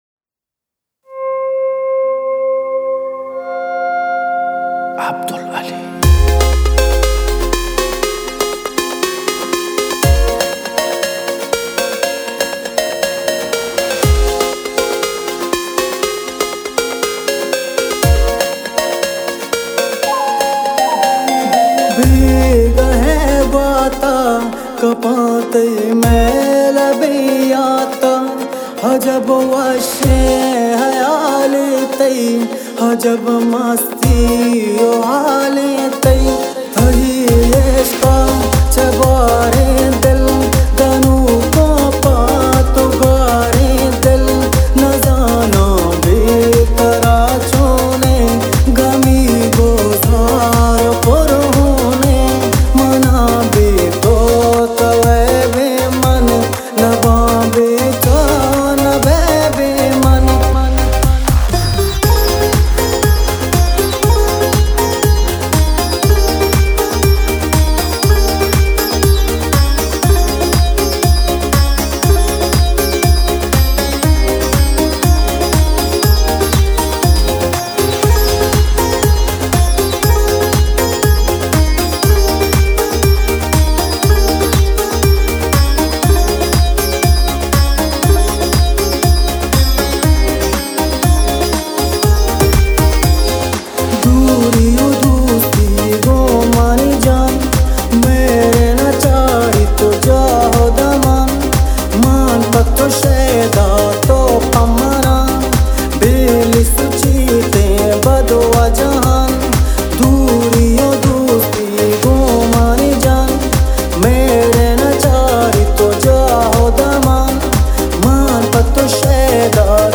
آهنگ بلوچی